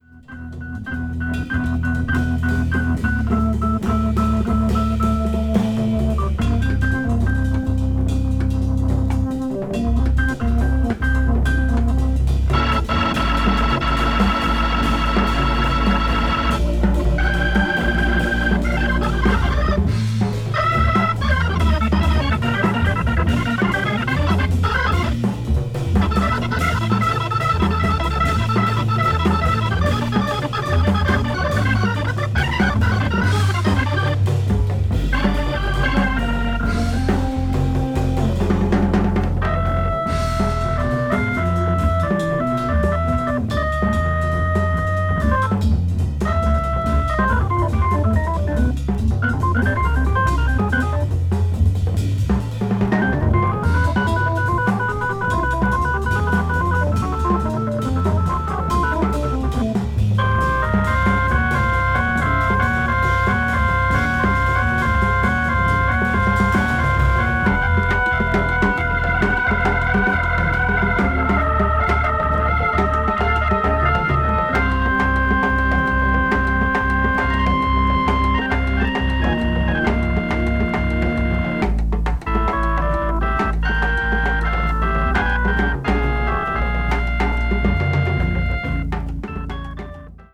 avant-jazz   contemporary jazz   free jazz   spiritual jazz